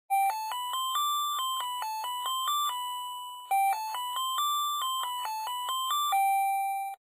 ZVONČEK 16 MELÓDIÍ
• elektronický
• zvuk: 16 striedajúcich sa druhov melódií